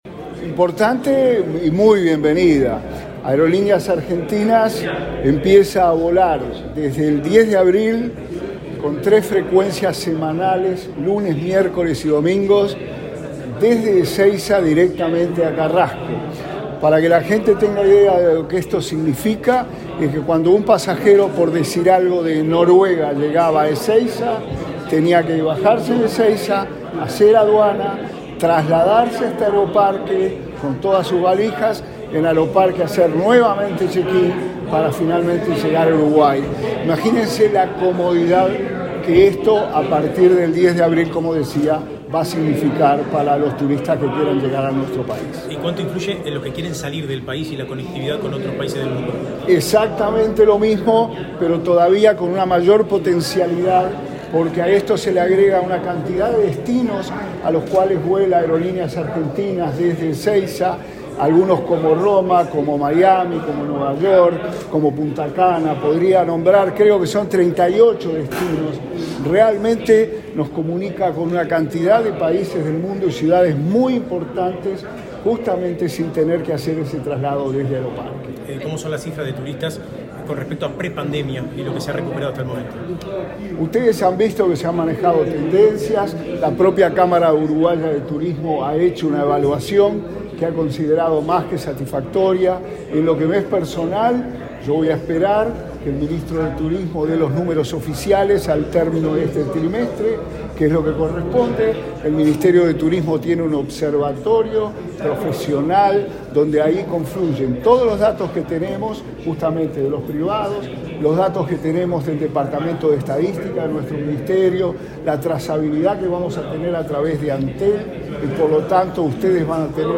Declaraciones del ministro interino de Turismo, Remo Monzeglio
Luego dialogó con la prensa.